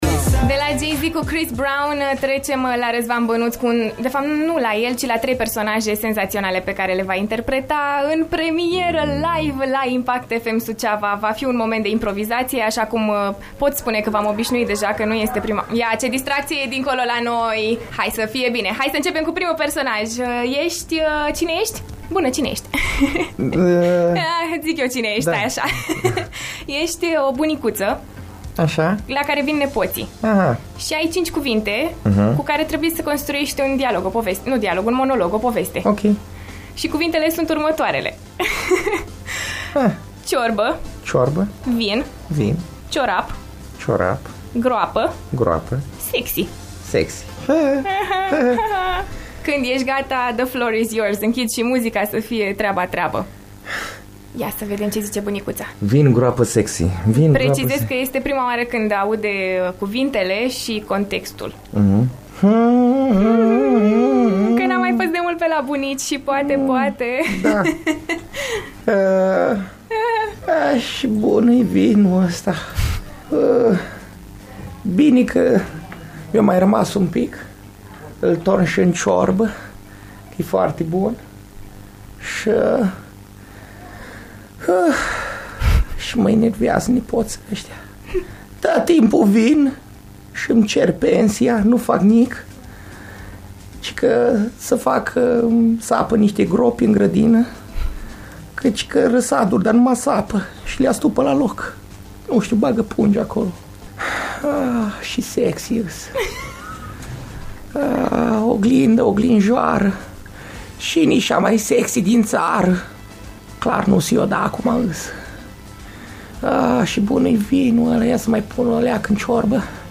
un super moment de improvizație live, în emisiunea Cosmica la After Morning. Cică erau odată o bătrânică, un instalator de urși bionici și un gropar în studio la Impact FM Suceava…